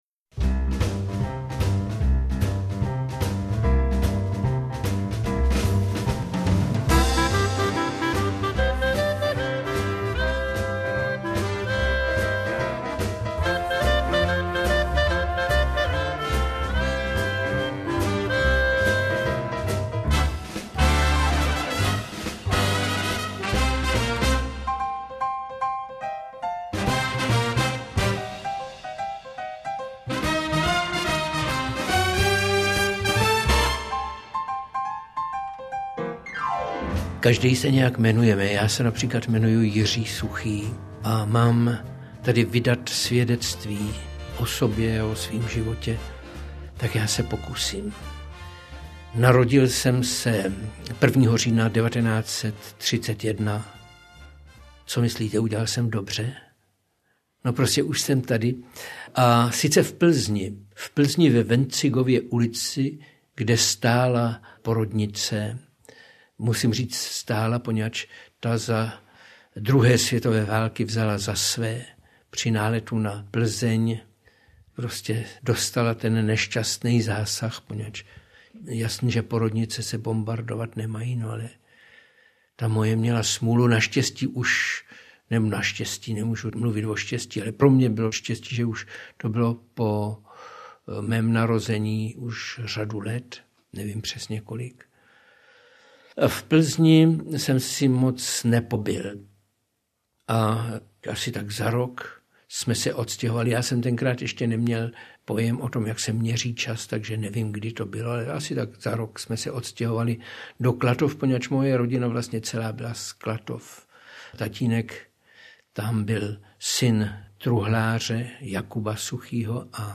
Interpret:  Jiří Suchý
Rozhlasové vzpomínky herce, zpěváka, textaře, spisovatele a zakladatele divadla Semafor.